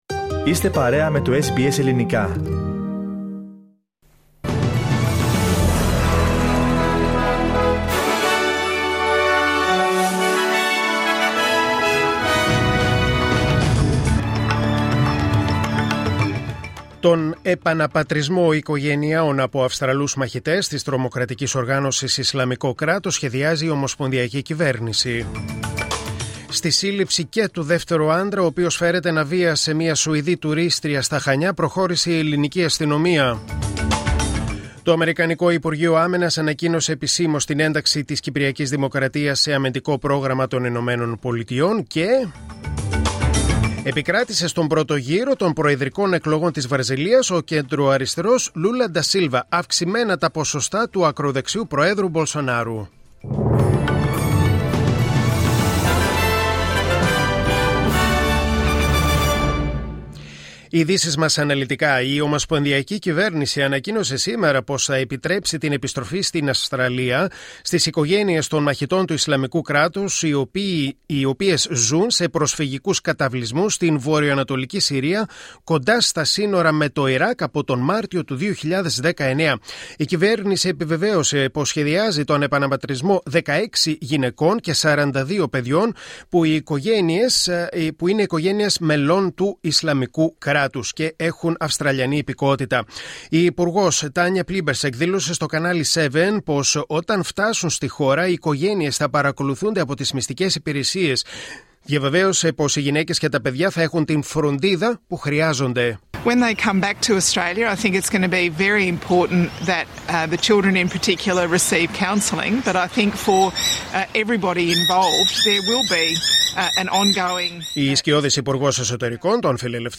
Δελτίο Ειδήσεων: Δευτέρα Monday 3.10.2022